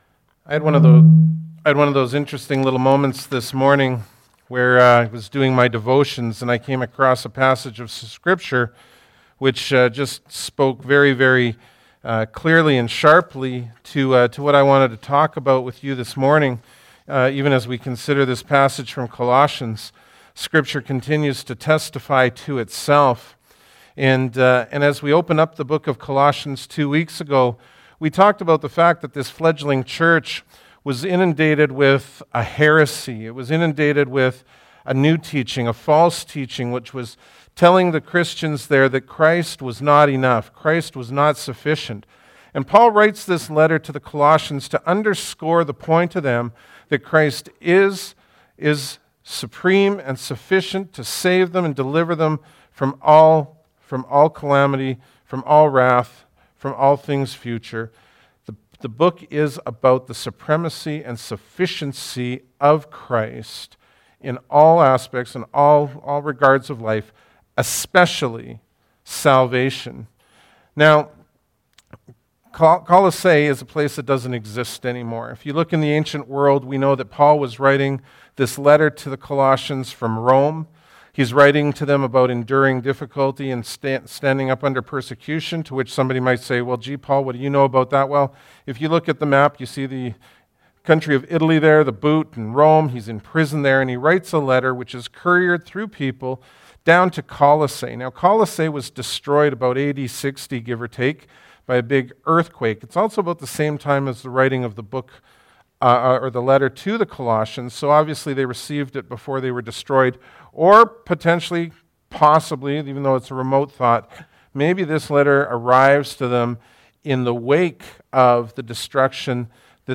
Bible Text: Colossians 1:15-23 | Preacher